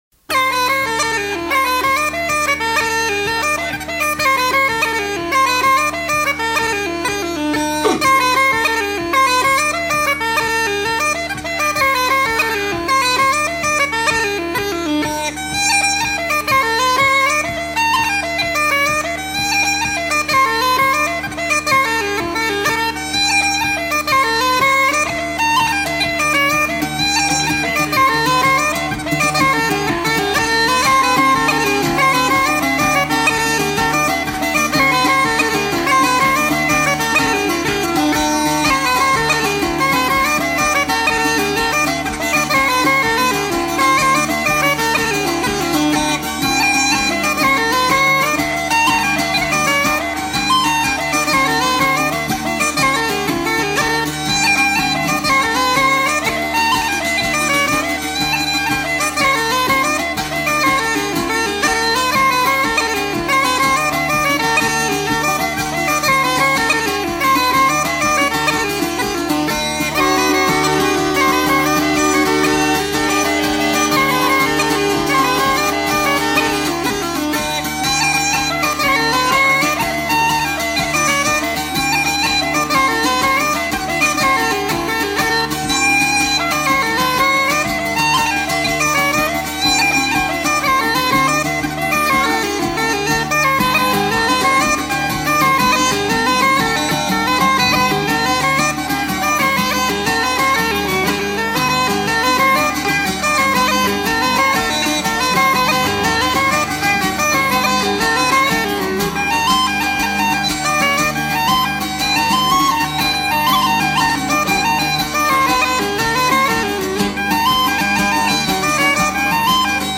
danse : jig
Pièce musicale éditée